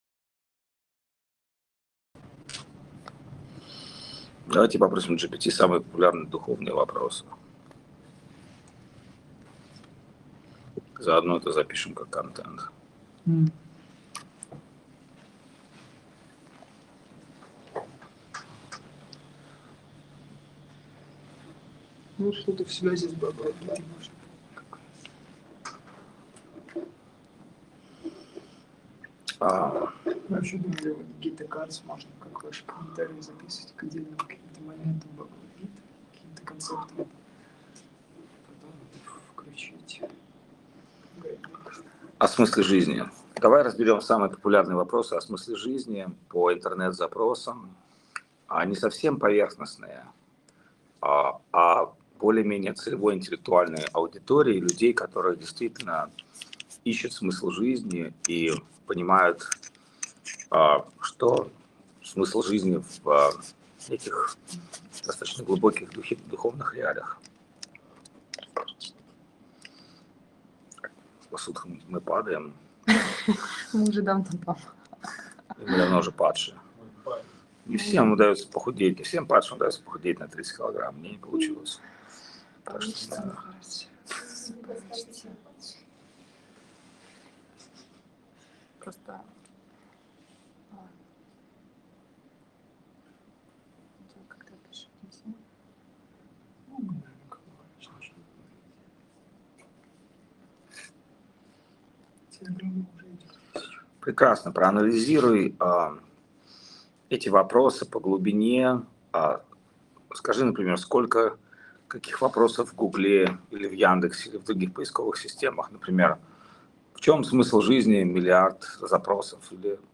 Чиангмай, Таиланд
Лекции полностью